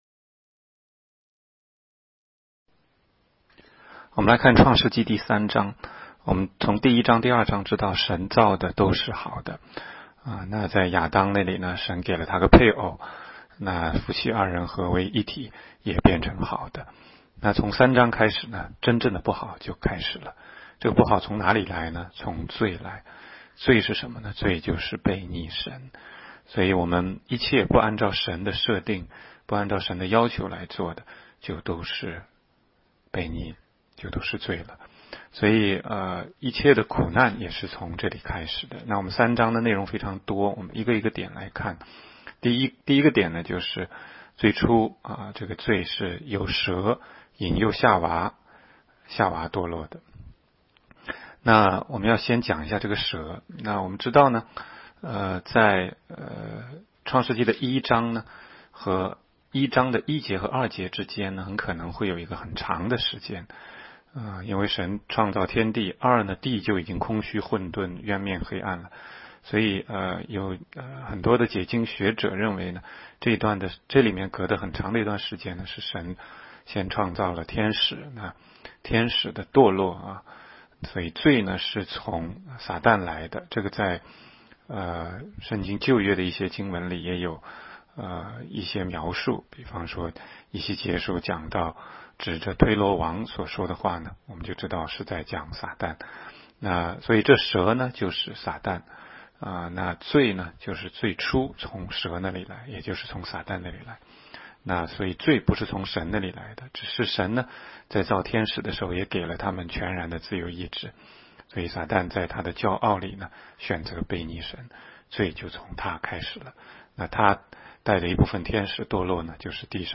16街讲道录音 - 每日读经-创3章